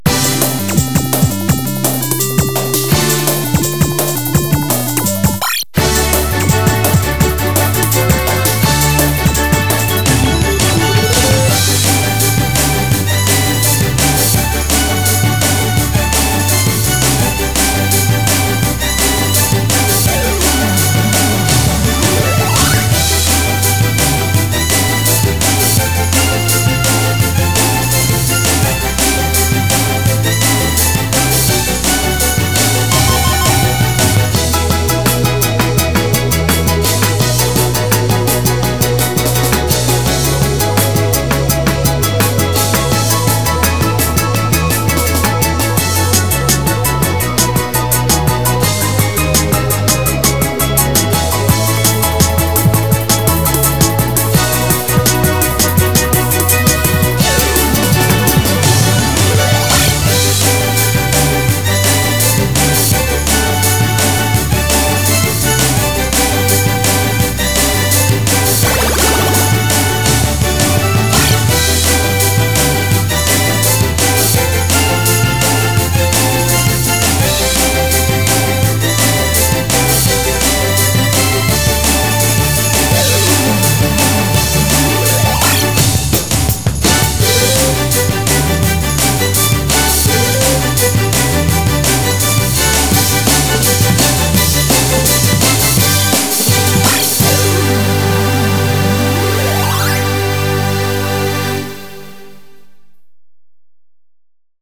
BPM168
Better quality audio.